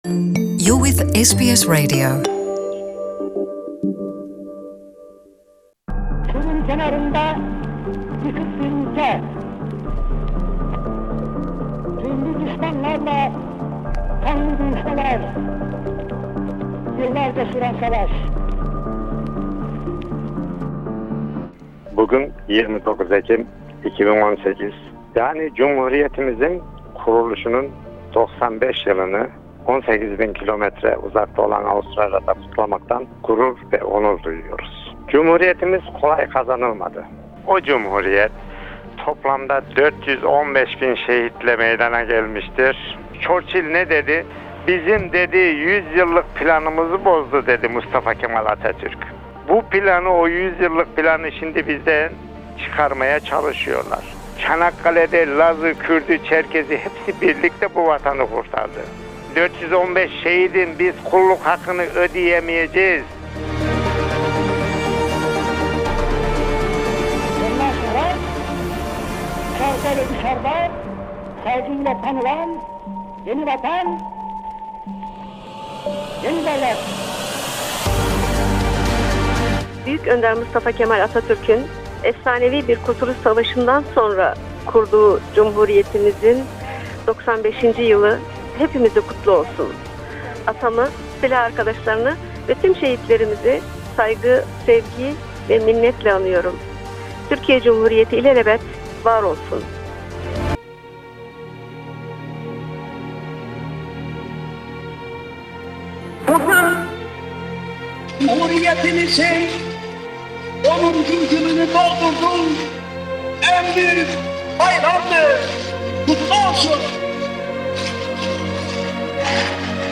Melbourne'de Federasyon Meydanı’nda düzenlenen Cumhuriyet Bayramı törenine toplum bireylerimizinde katıldı. İştiklal Marşı eşliğinde Türk bayrağının göndere çekilnesinin ardından Melbourne Başkonsolosluğu görevini vekaleten sürdüren Canberra büyükelçiliği Birinci Müsteşarı Osman İlhan Şener kısa bir konuşma yaptı.